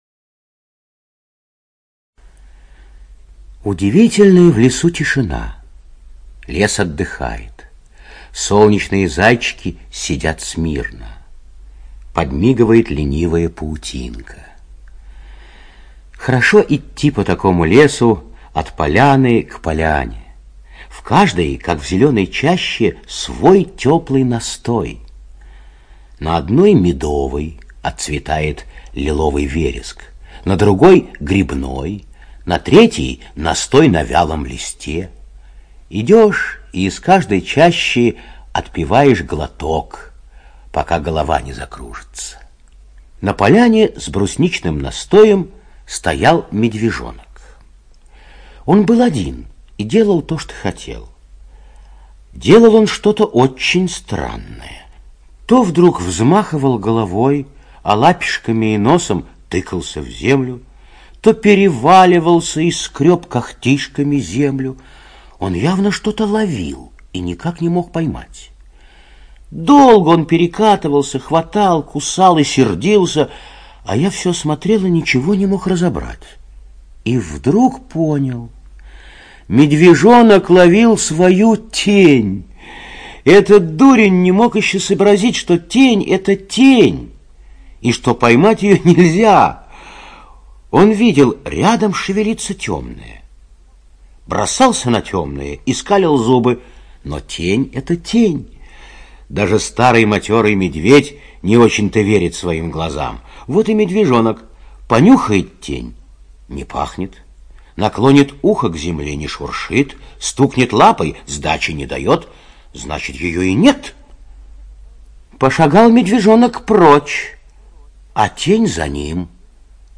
ЖанрСказки